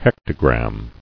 [hec·to·gram]